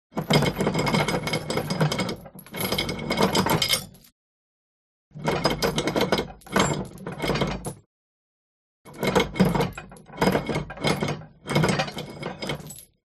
Звук цепей опускающегося подъемного моста в крепость